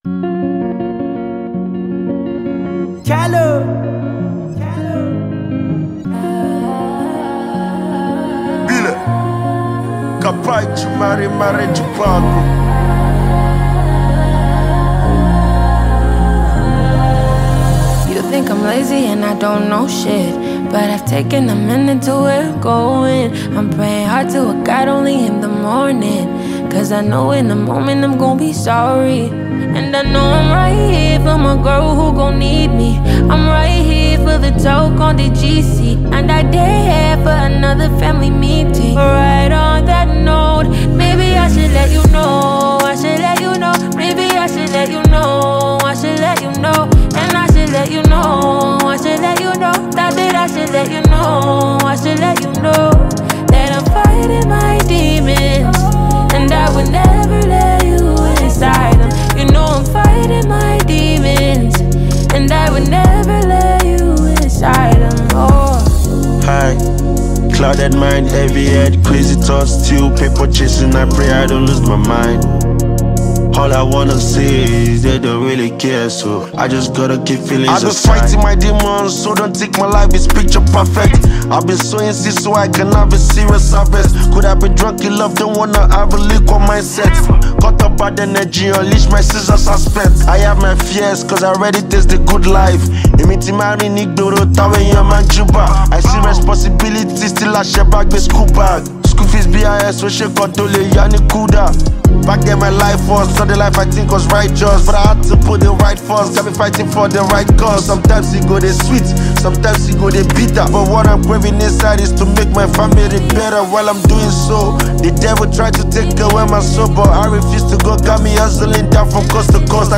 powerful tune